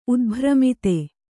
♪ udbhramite